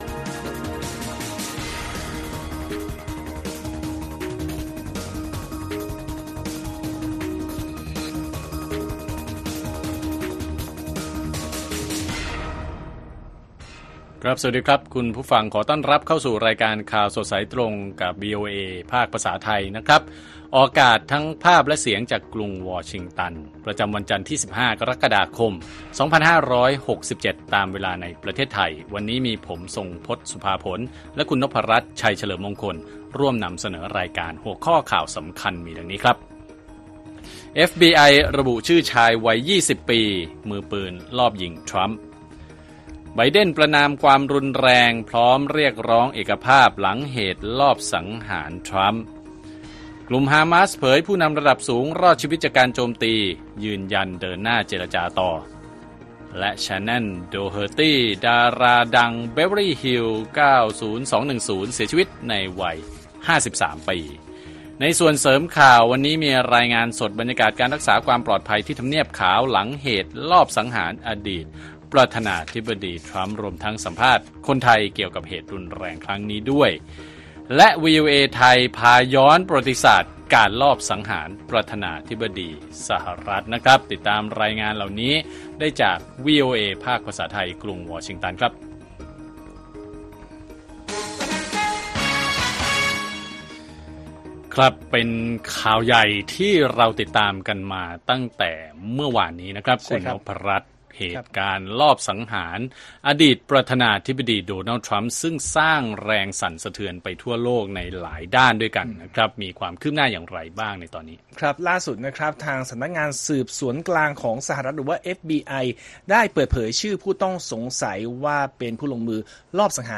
ข่าวสดสายตรงจากวีโอเอไทย จันทร์ ที่ 15 ก.ค. 67